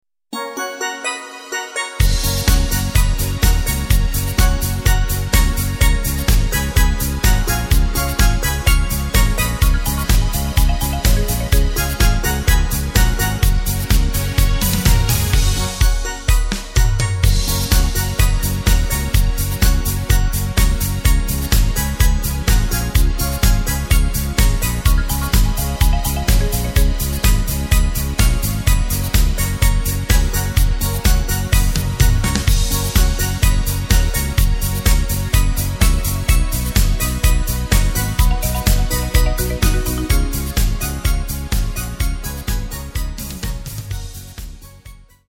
Takt: 4/4 Tempo: 126.00 Tonart: F#
Discofox
mp3 Playback Demo